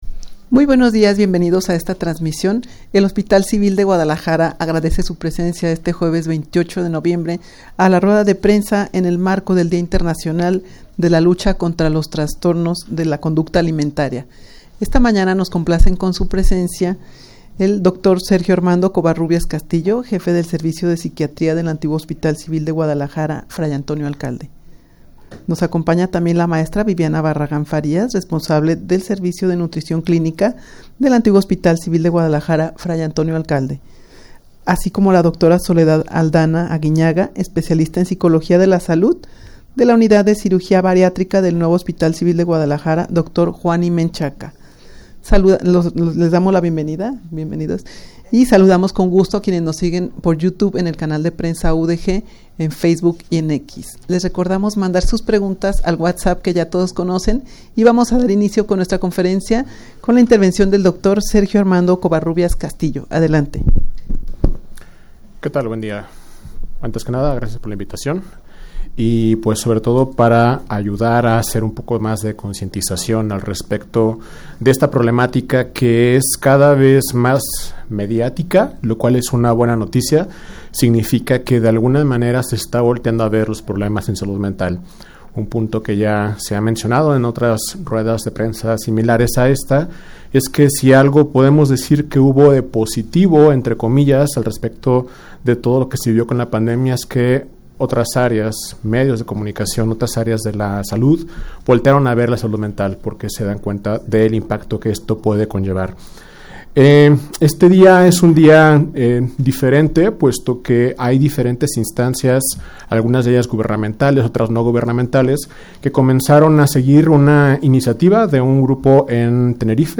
Audio de la Rueda de Prensa
rueda-de-prensa-en-el-marco-del-dia-internacional-de-lucha-contra-los-trastornos-de-la-conducta-alimentaria.mp3